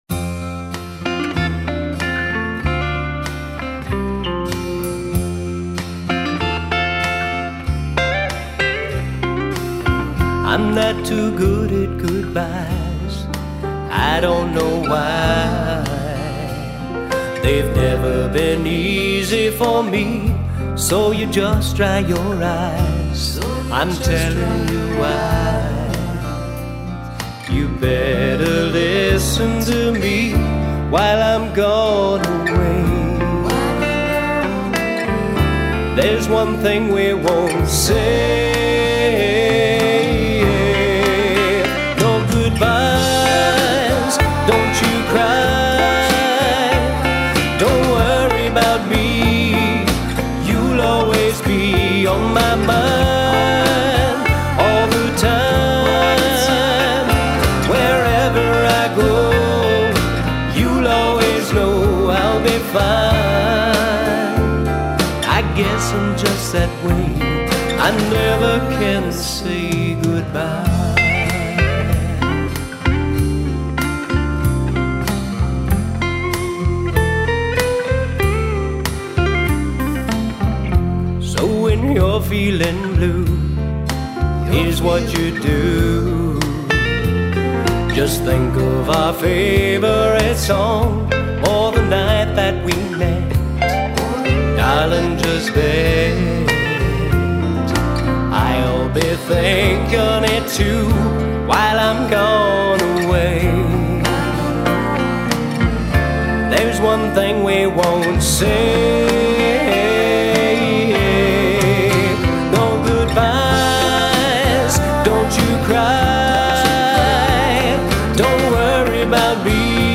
Mid Tempo Feel Good